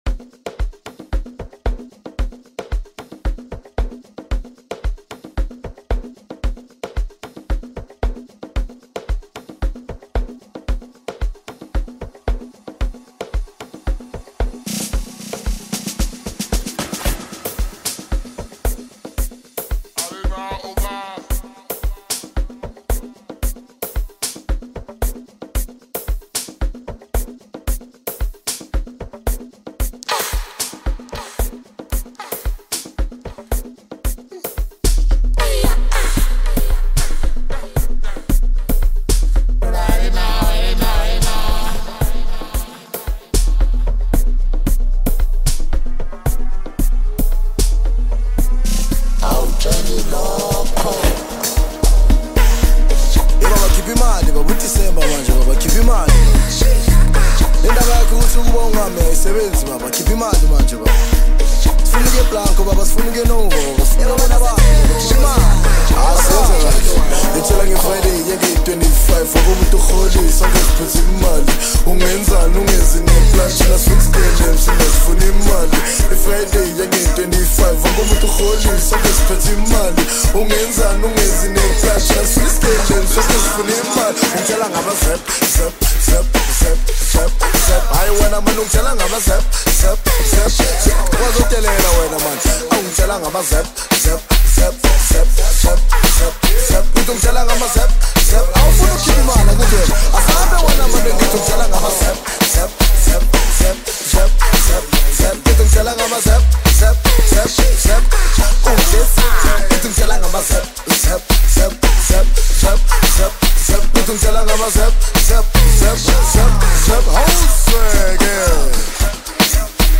AmapianoSouth African Music
high-energy Amapiano single
club-ready production